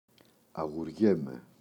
αγουριέμαι [aγuꞋrʝeme]